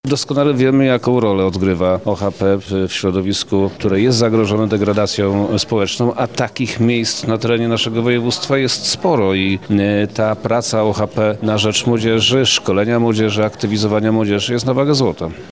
Praca na rzecz młodzieży – szkolenia i aktywizacja jest na wagę złota – zaznacza wojewoda lubelski, prof. Przemysław Czarnek.